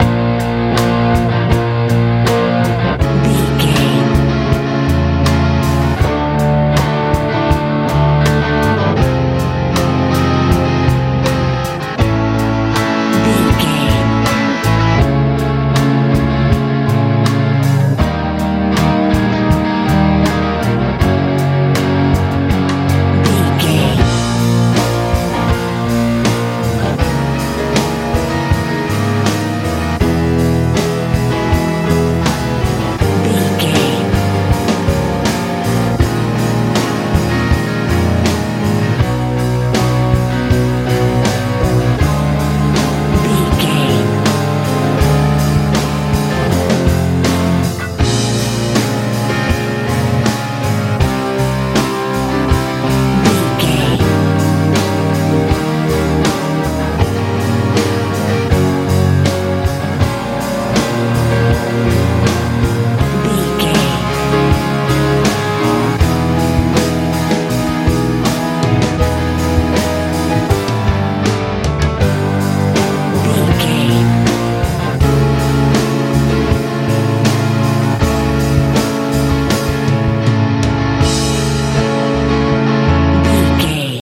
modern rock feel
Ionian/Major
B♭
piano
electric guitar
bass guitar
drums
heavy
lively
mellow